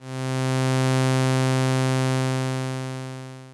synth13.wav